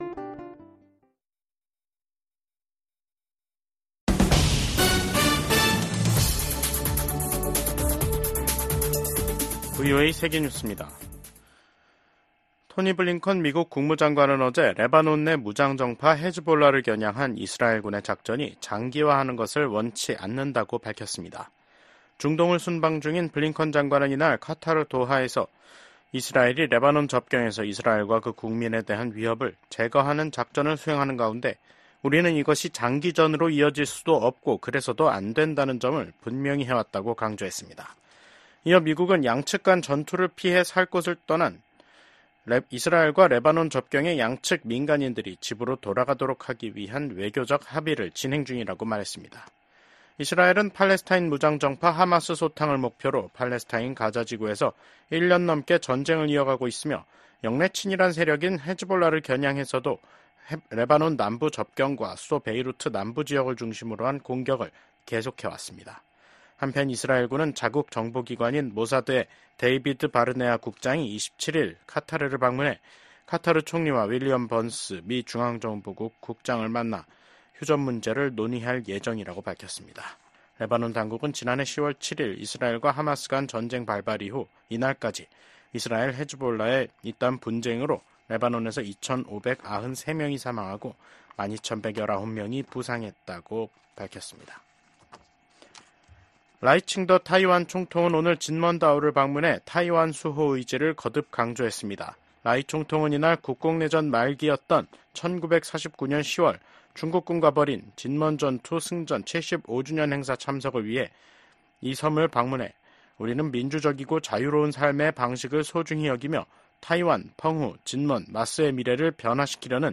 VOA 한국어 간판 뉴스 프로그램 '뉴스 투데이', 2024년 10월 25일 2부 방송입니다. 미국 국방부는 러시아에 파병된 북한군이 우크라이나에서 전쟁에 참여할 경우 러시아와 함께 공동 교전국이 될 것이라고 경고했습니다. 미국 하원 정보위원장이 북한군의 러시아 파병과 관련해 강경한 대응을 촉구했습니다.